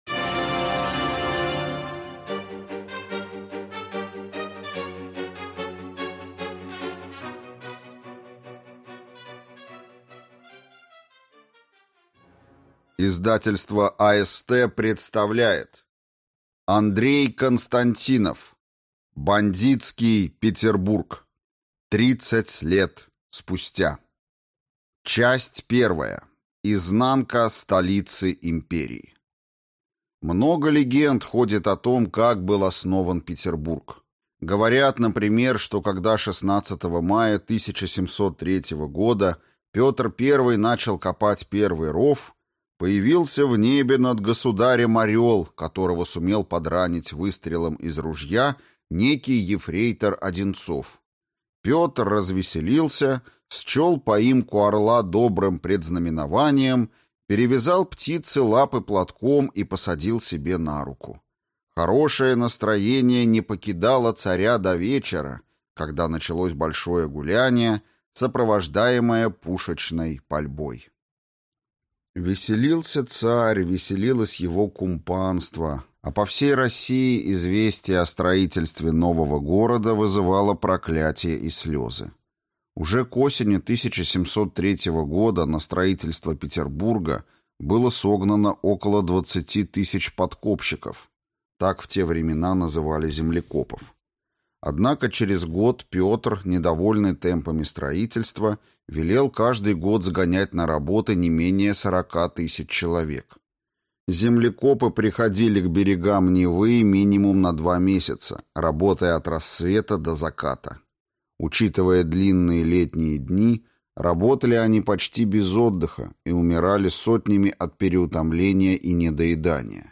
Аудиокнига Бандитский Петербург. Часть первая. Изнанка столицы империи | Библиотека аудиокниг